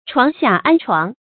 床下安床 chuáng xià ān chuáng
床下安床发音